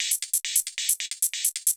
Index of /musicradar/ultimate-hihat-samples/135bpm
UHH_ElectroHatA_135-05.wav